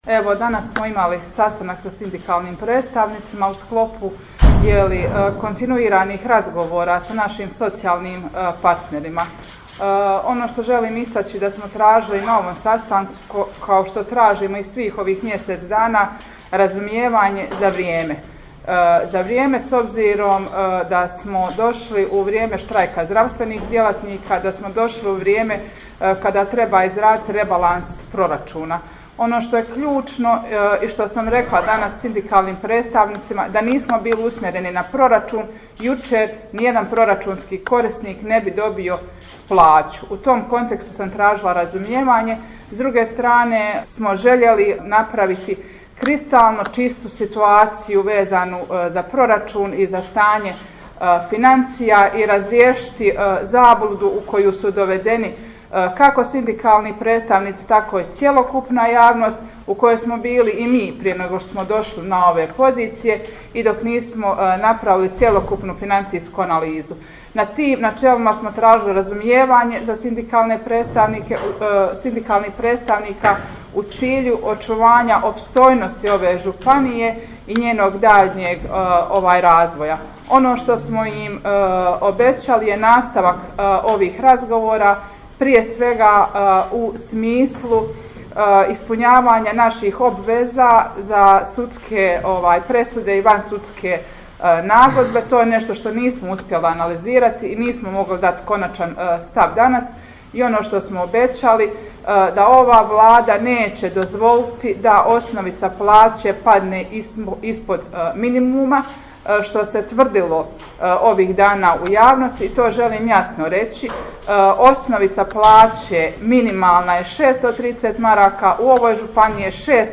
Audio: Predsjednica Marija Buhač